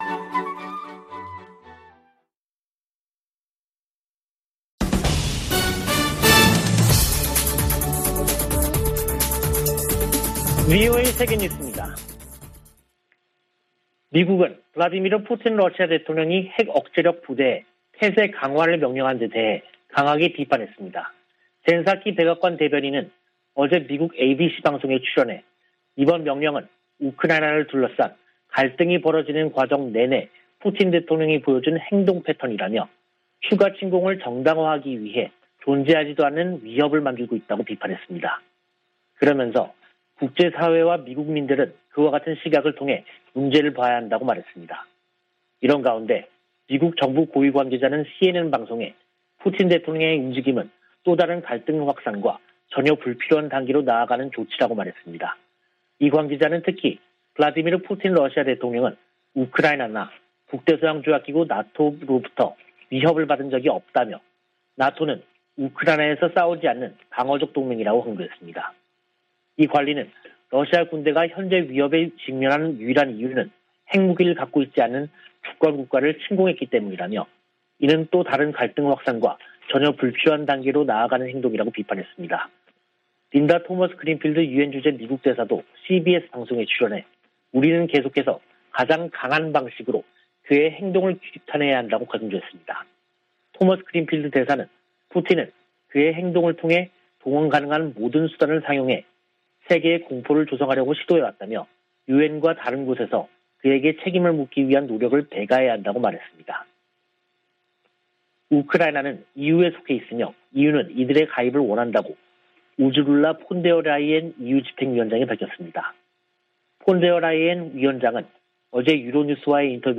VOA 한국어 간판 뉴스 프로그램 '뉴스 투데이', 2022년 2월 28일 2부 방송입니다. 북한은 27일 발사한 준중거리 탄도미사일이 정찰위성에 쓰일 카메라 성능을 점검하기 위한 것이었다고 밝혔습니다. 미 국무부는 북한의 탄도미사일 시험 발사 재개를 규탄하고 도발 중단을 촉구했습니다. 미한일 외교∙안보 고위 당국자들이 전화협의를 갖고 북한의 행동을 규탄하면서 3국 공조의 중요성을 거듭 강조했습니다.